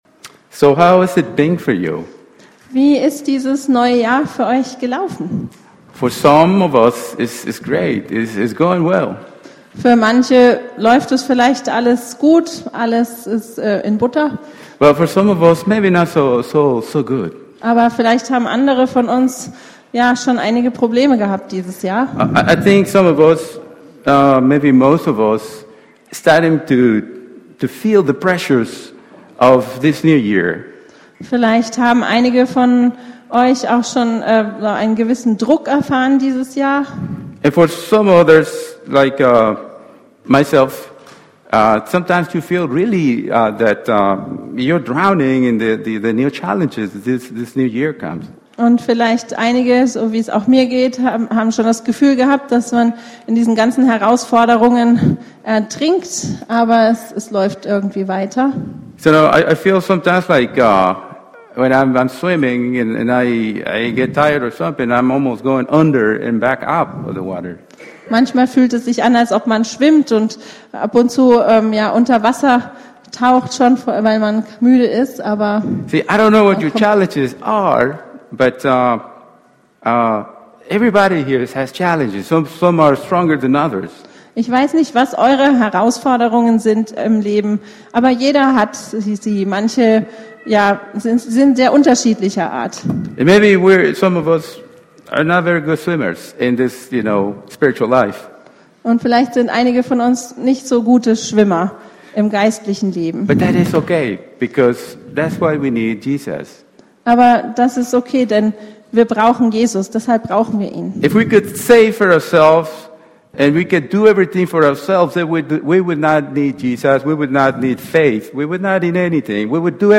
Predigt 22.Feb. 2020